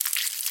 Sound / Minecraft / mob / silverfish / step4.ogg
step4.ogg